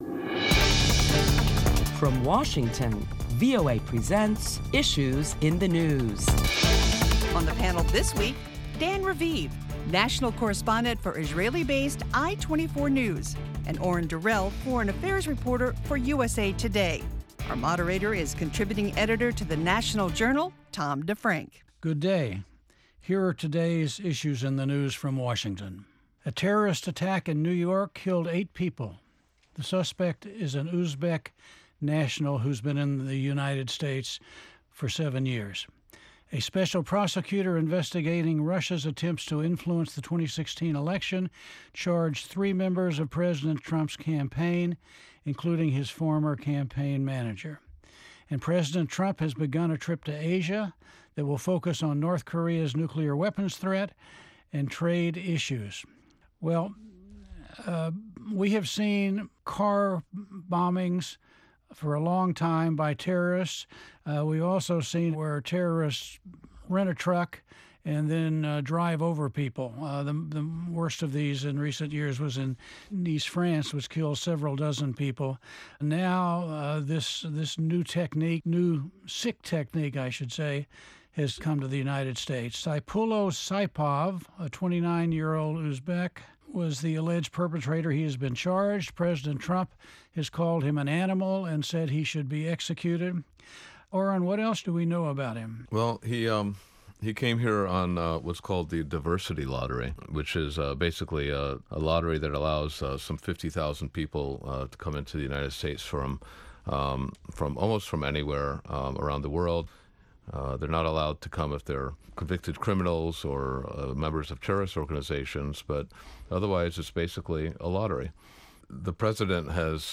Prominent Washington correspondents discuss the week’s top headlines including the recent terrorist attack in New York City and its implications for future immigration to the United States.